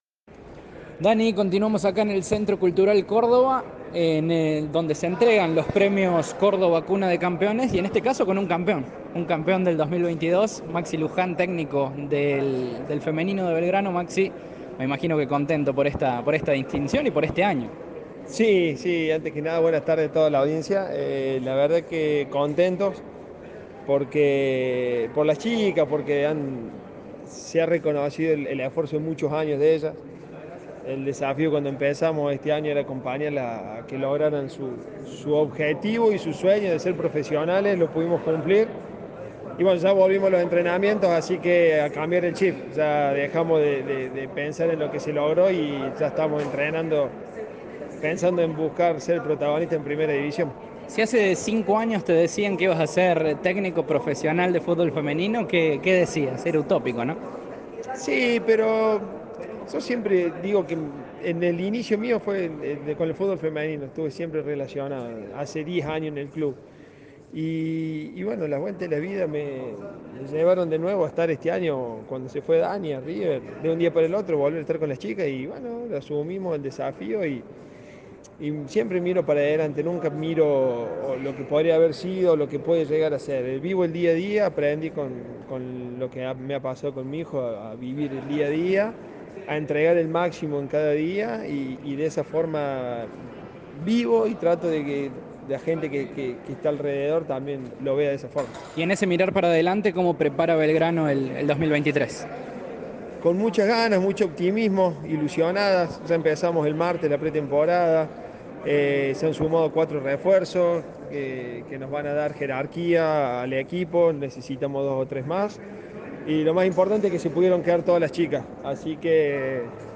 dialogaron con Cadena 3
En el Centro Cultural Córdoba se llevó a cabo la 43° edición de los premios "Córdoba, cuna de campeones", organizada por el Círculo de Periodistas Deportivos.